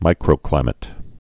(mīkrō-klīmĭt)